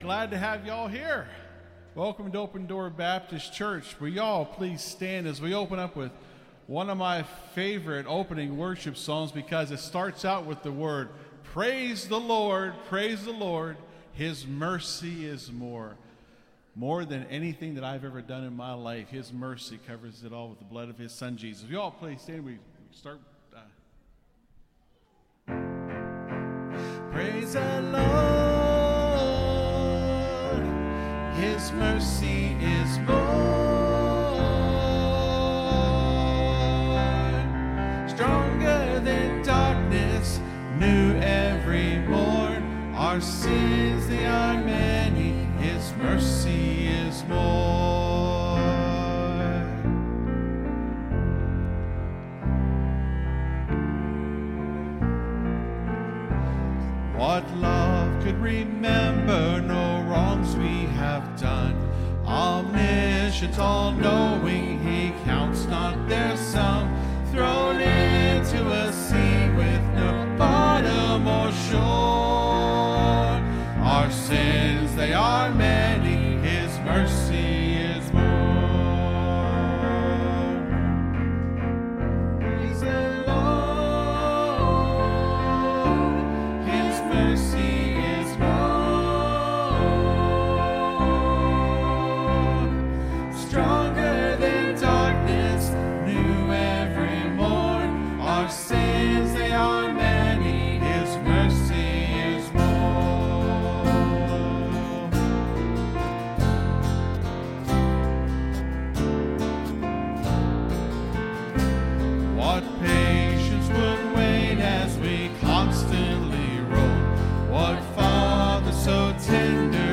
(Sermon starts at 25:40 in the recording).